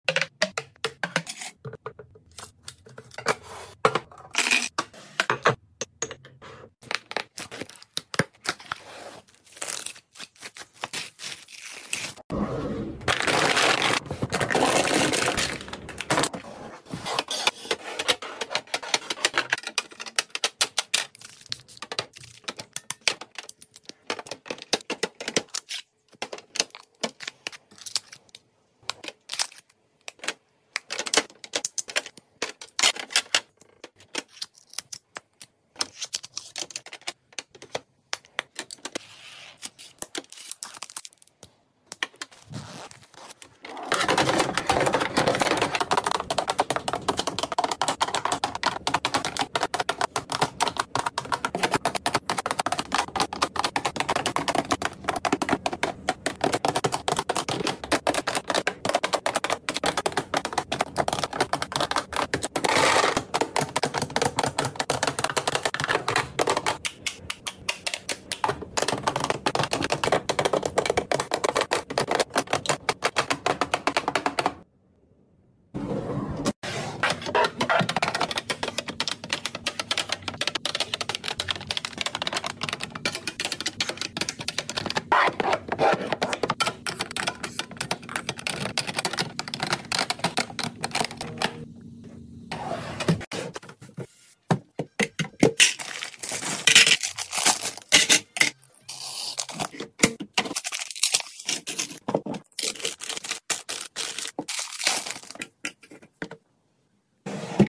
ASMR Organizing Makeup Drawer 💄💄💄 sound effects free download